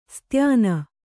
♪ styāna